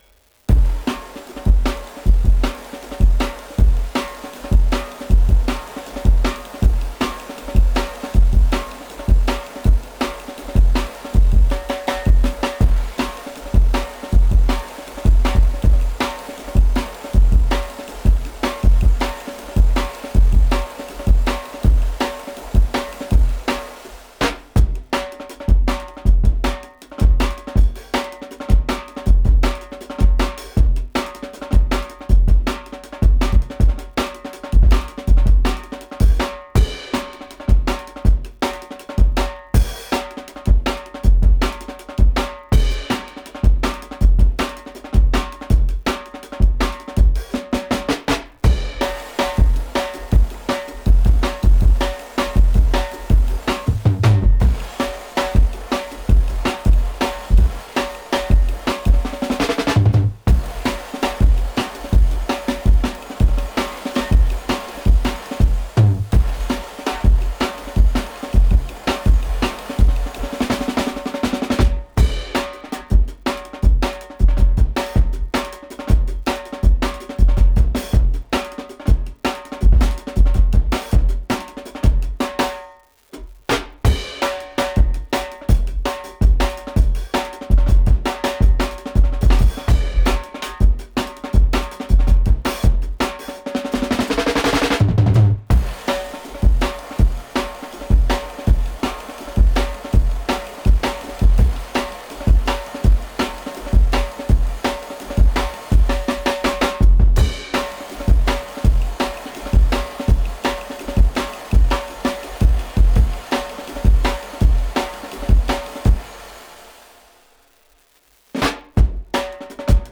Index of /4 DRUM N BASS:JUNGLE BEATS/THE RAIN RIDE CYMBAL
THE RAIN RIDE CYMBAL.wav